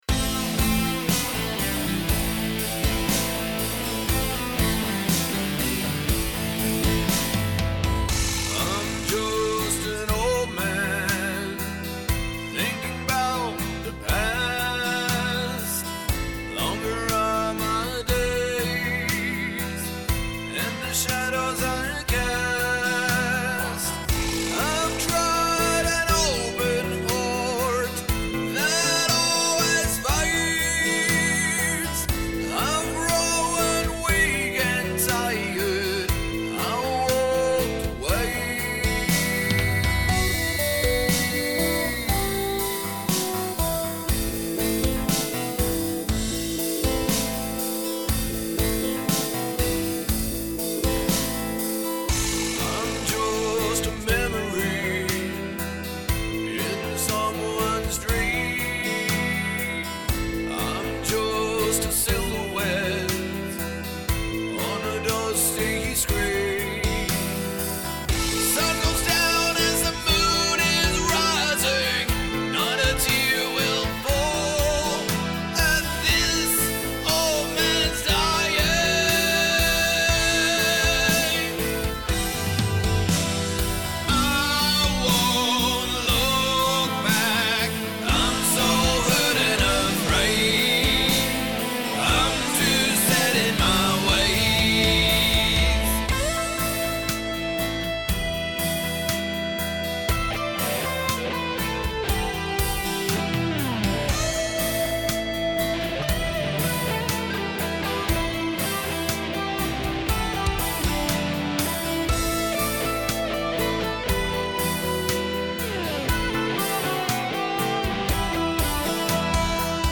Vocals
Drums